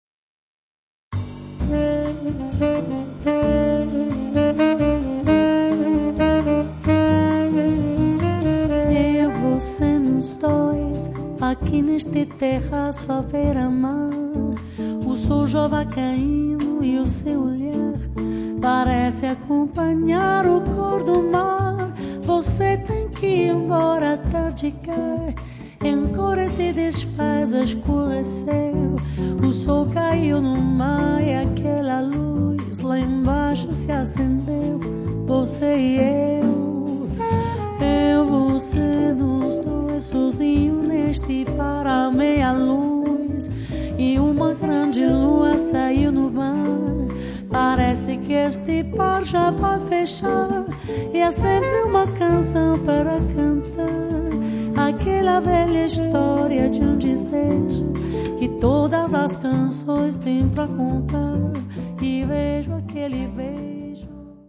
vocal, arrangements
Saxophone
guitar
double bass
drums
Brani raffinati, di corposa eleganza.